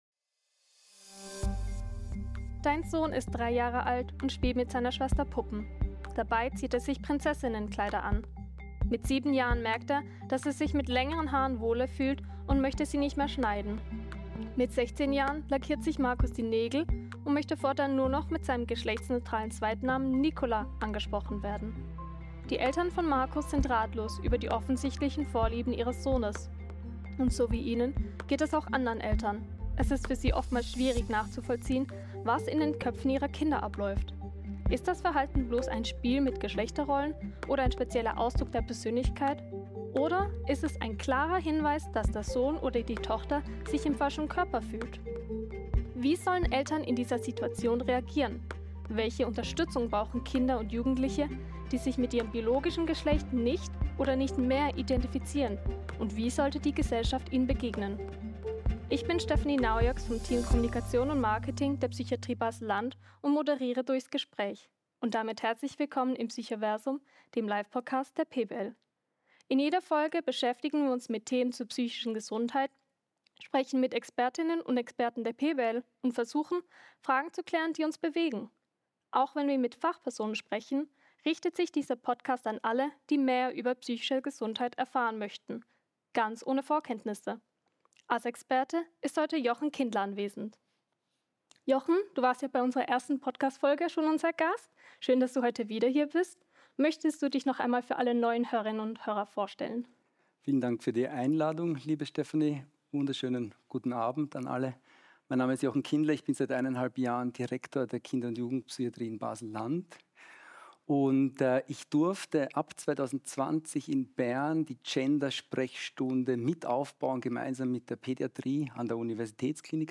Wir diskutieren mit fachlicher Tiefe und Empathie die brisante politische Ebene hinter diesem emotionalen Thema, ordnen wichtige Begriffe ein und klären allerlei Mythen über Geschlechtsidentitäten auf.